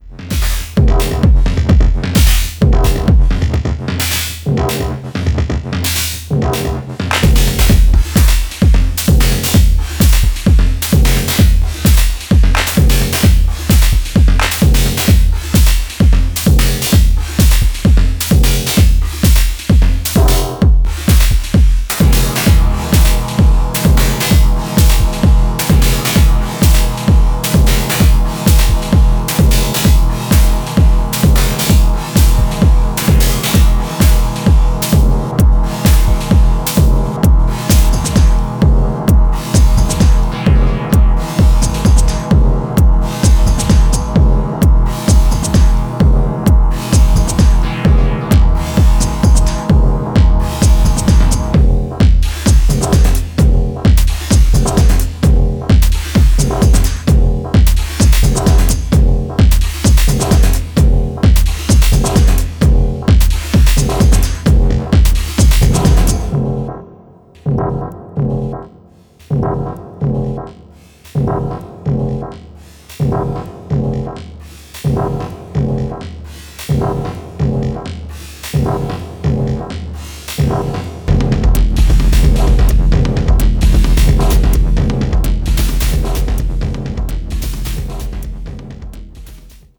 アフロ・オリエンテッドな要素とボトムヘヴィなリズムを組み合わせたダークでエネルギッシュなトラック群を展開しており